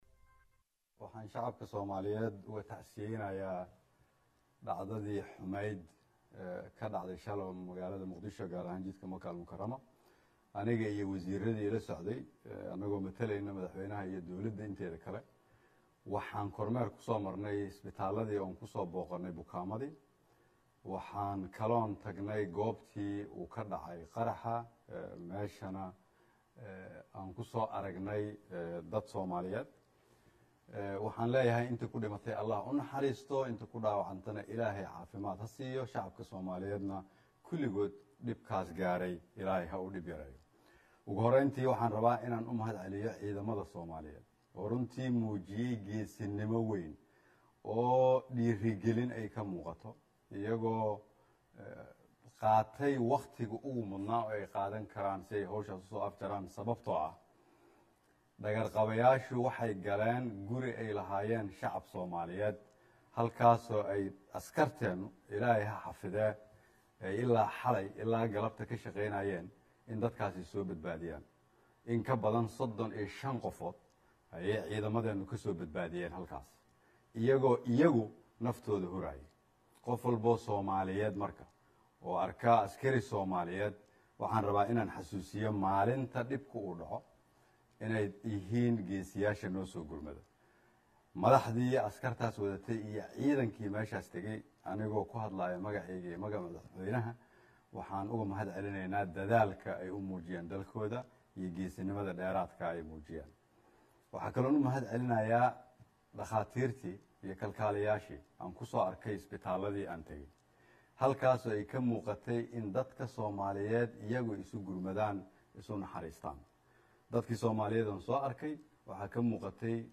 Ra'isul wasaare Xasan Cali Khayre oo Warbaahinta Qaranka kala hadlay Shacabka Soomaaliyeed kadib markii uusoo kormeeray isbitaallada iyo goobtii argigixisadu